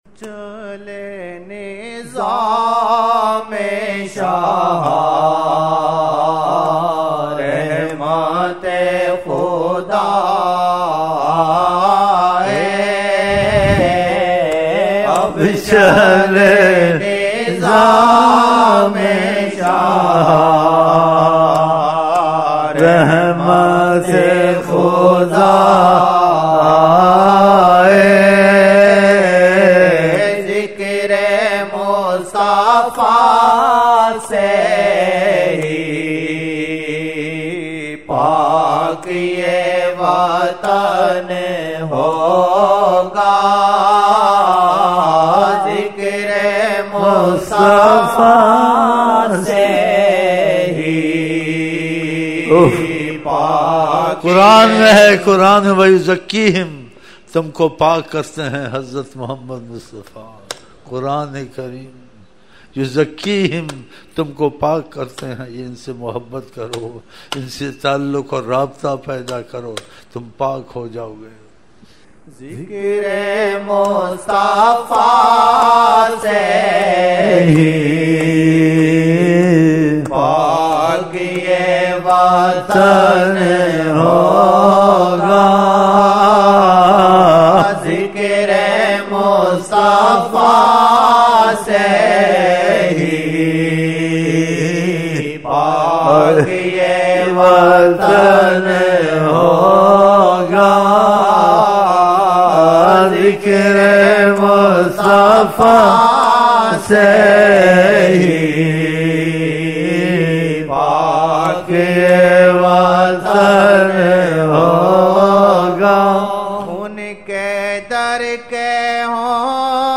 silsila ameenia | » Naat-e-Shareef